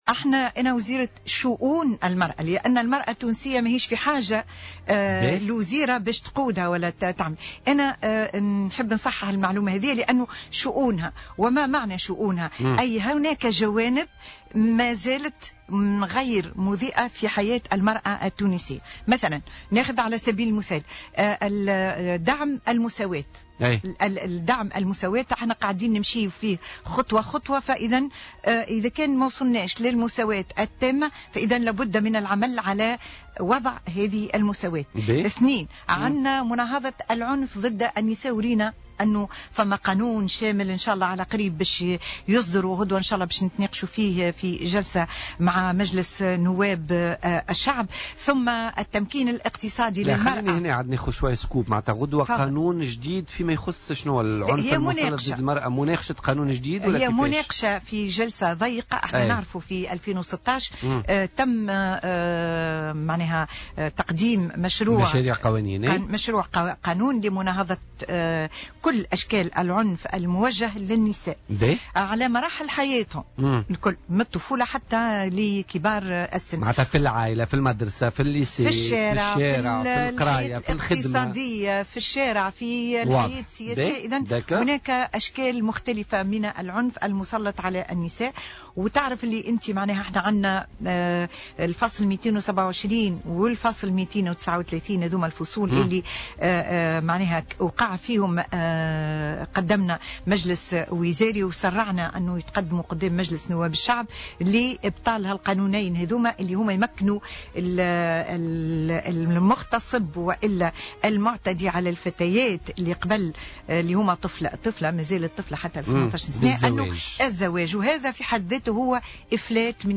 وأكدت الوزيرة خلال مداخلة لها اليوم في برنامج "بوليتيكا" أن العقاب ضروري ويعتبر وسيلة من وسائل الردع، مشيرة إلى أن جلسة ضيقة ستنعقد غدا بمجلس نواب الشعب لمناقشة مشروع القانون المتعلق بمناهضة العنف ضد المرأة.